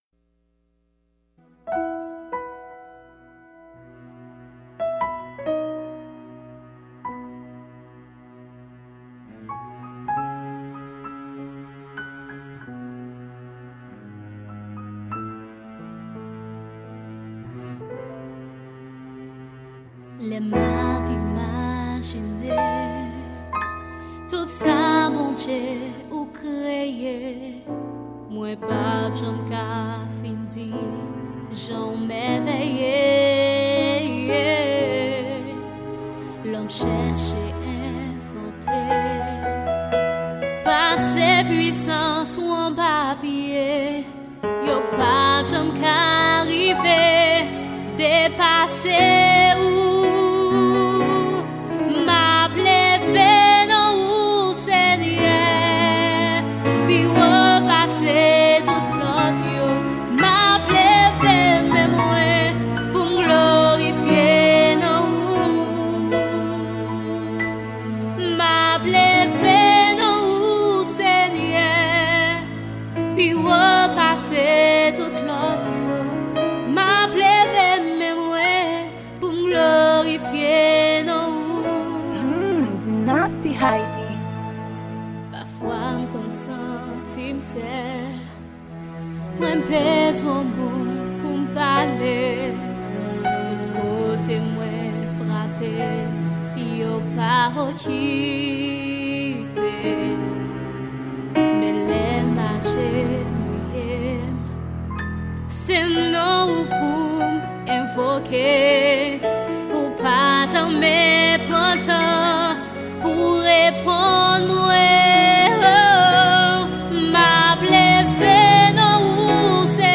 Genre: Evangile.